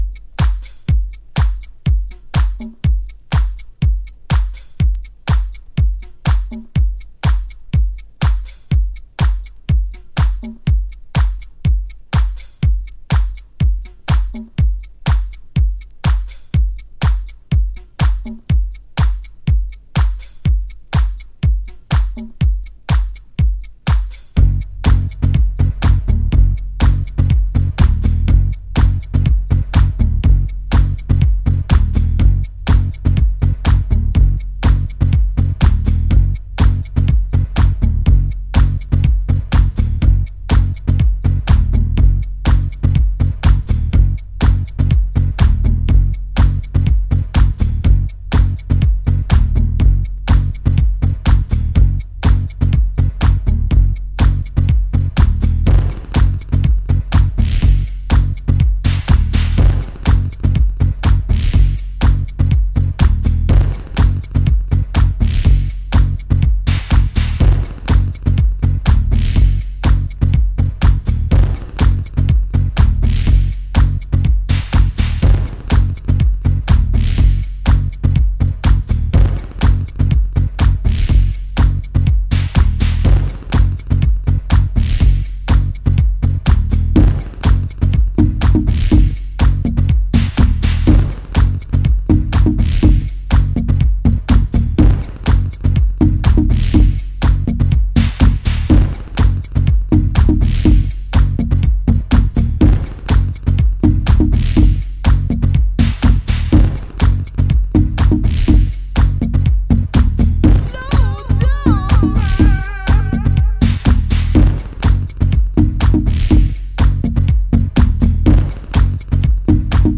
House / Techno
トリッキーでファンキー、そしてジャンクなマッドネスが見え隠れするハウストラックス。黒、湿、重の三拍子揃ってます。